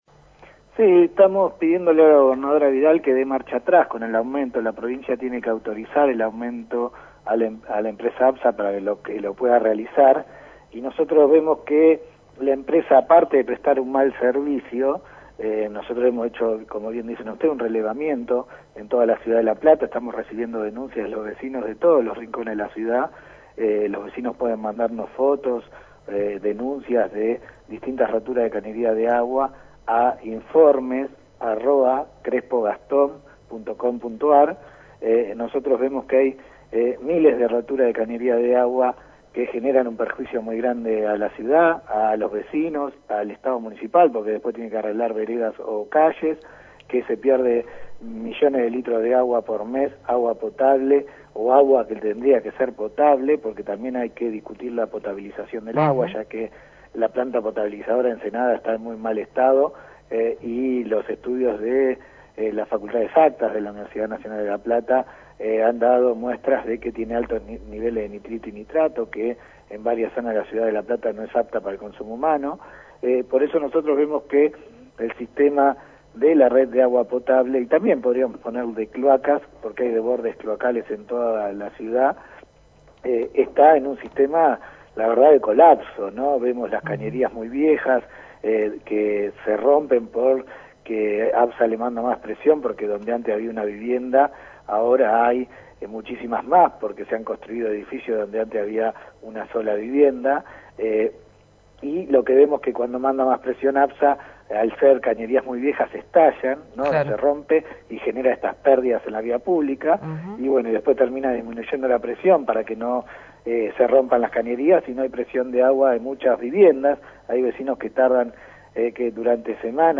(23/05/2016) Gastón Crespo, Concejal del partido GEN, charló con Clase Turista sobre la actualidad del servicio de ABSA y el pedido de que se de marcha atrás con el aumento del agua.